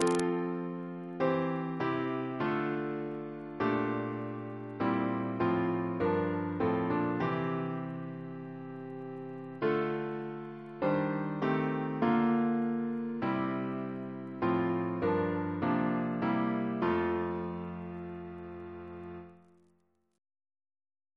Double chant in F Composer: Chris Biemesderfer (b.1958)